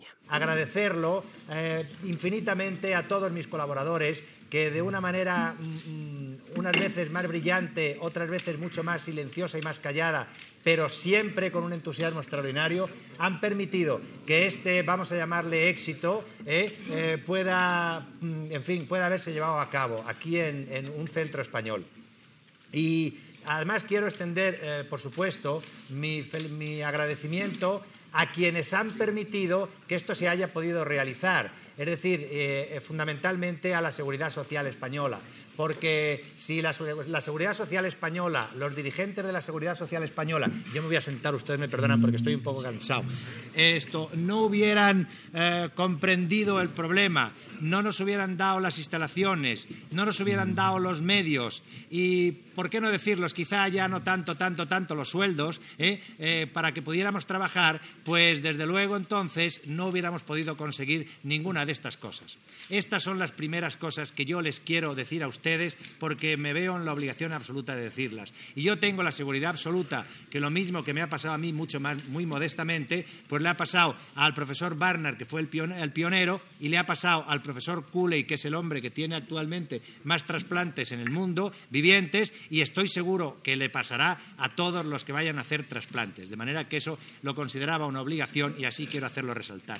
Declaracions del doctor Cristobal Martínez-Bordiú, a l'Hospital de La Paz de Madrid, després de fer el primer transplantament de cor a Espanya
Informatiu
Extret del programa "El sonido de la historia", emès per Radio 5 Todo Noticias el 22 de setembre de 2012